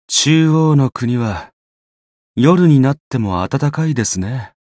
觉醒语音 中央の国は、夜になっても暖かいですね 媒体文件:missionchara_voice_66.mp3